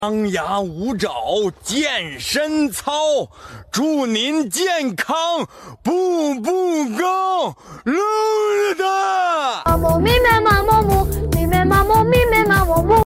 The Chinese man screaming and sound effects free download